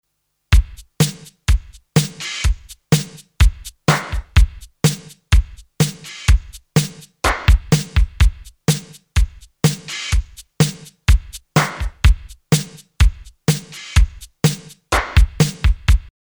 Ich hab mal die Drumtracks Clap in den S900 geladen, finde das funktioniert sehr gut.